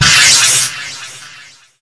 w-radiation.wav